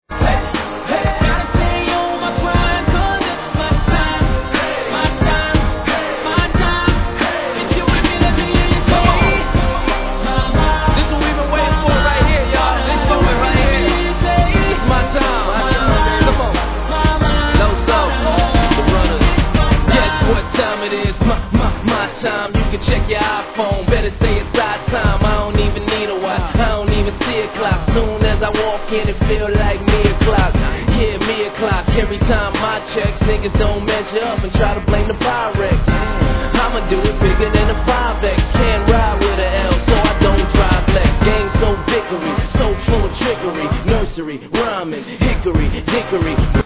Tag       EASTCOAST 　 HIP HOP